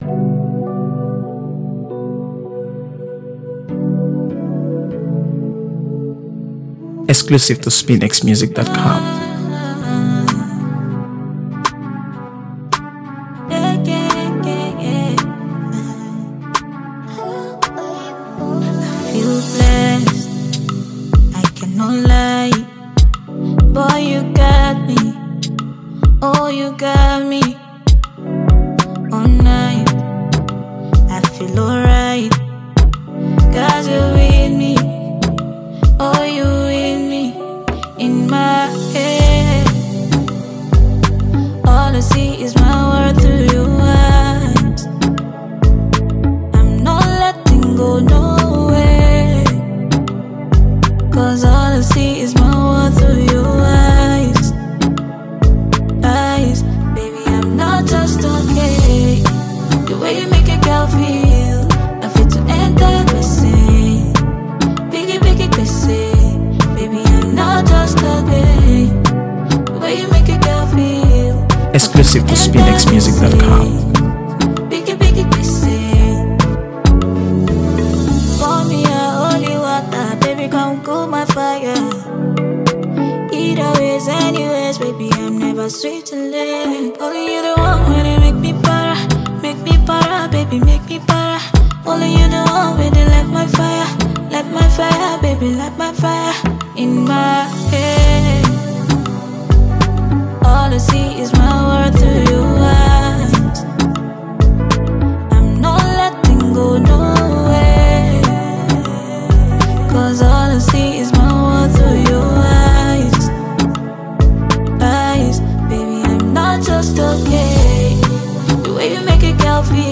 AfroBeats | AfroBeats songs
soothing and heartfelt track